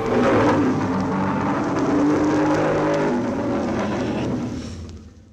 File:Wangmagwi Roar.ogg
Wangmagwi_Roar.ogg